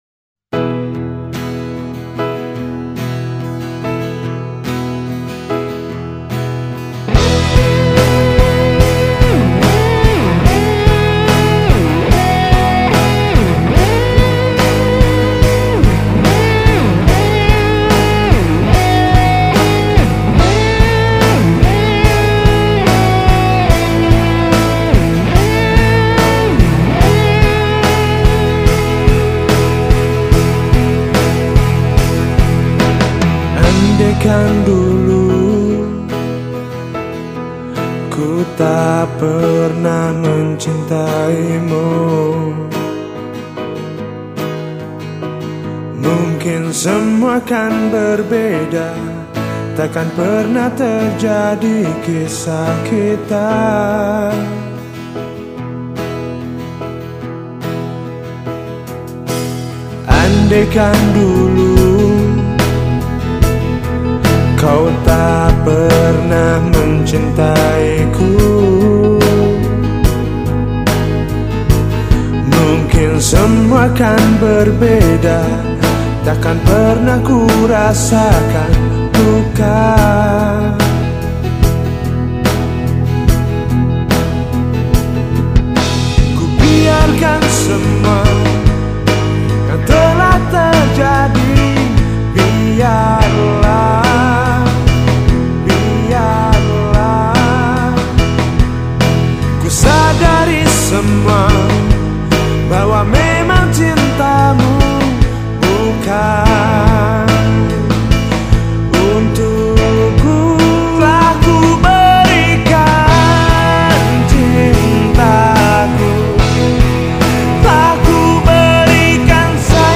2. POP